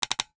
NOTIFICATION_Click_06_mono.wav